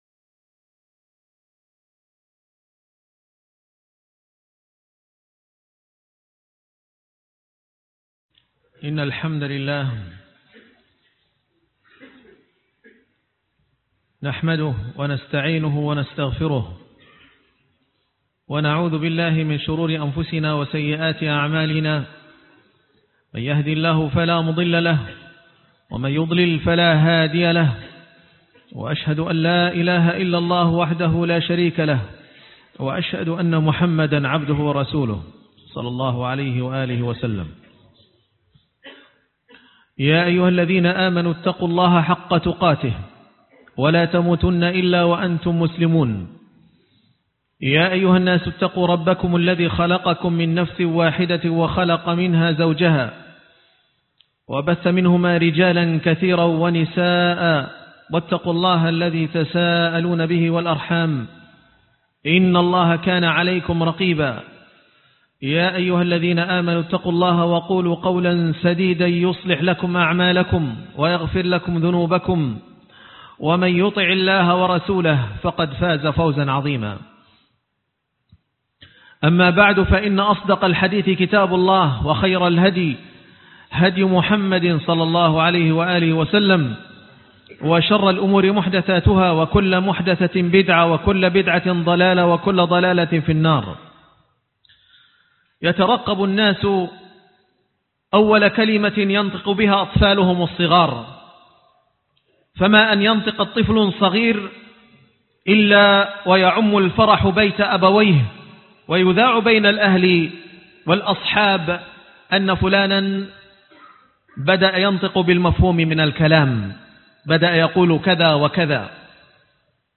نعمة الكلام - خطبة الجمعة